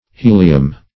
Helium \He"li*um\ (h[=e]"l[i^]*[u^]m), n. [NL., fr. Gr.